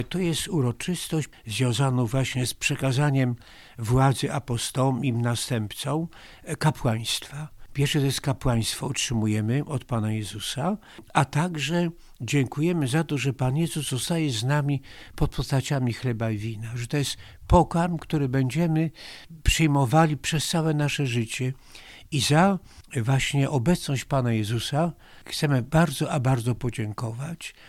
Jak bp Antoni Długosz tłumaczy tajemnicę Wielkiego Czwartku?